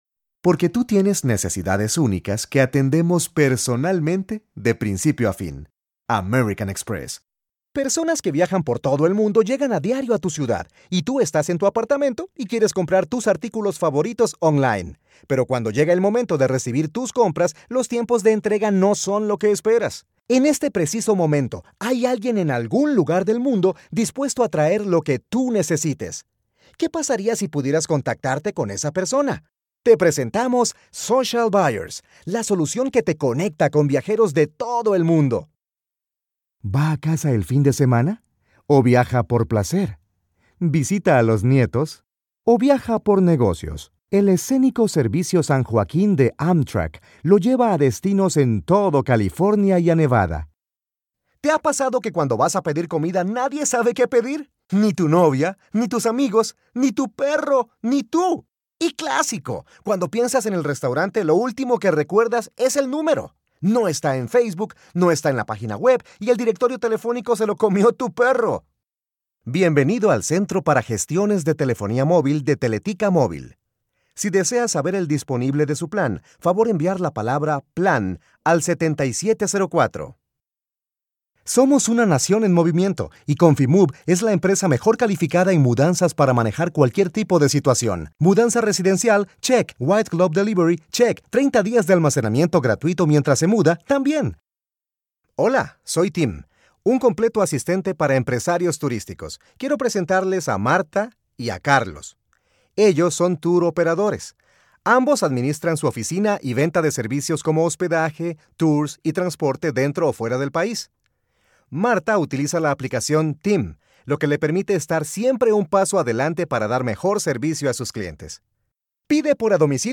Voice actor Actor de voz y locuctor home studio español Neutro
kolumbianisch
Sprechprobe: Industrie (Muttersprache):